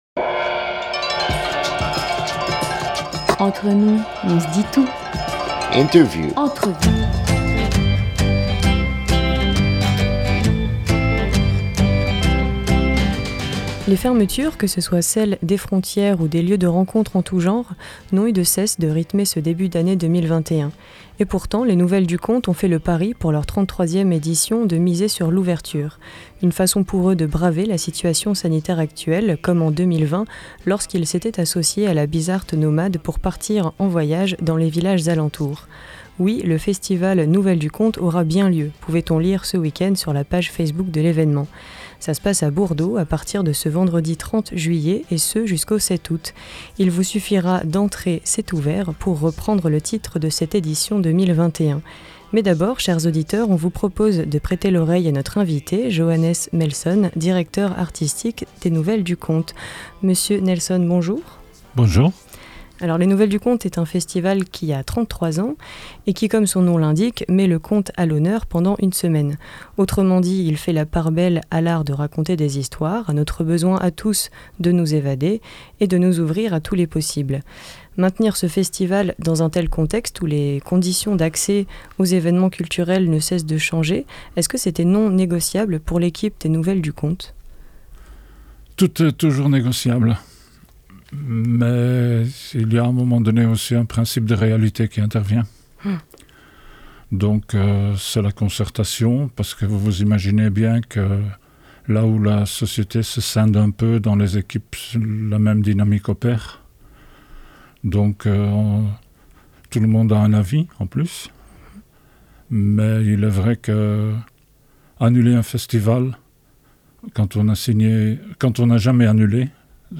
26 juillet 2021 17:40 | Interview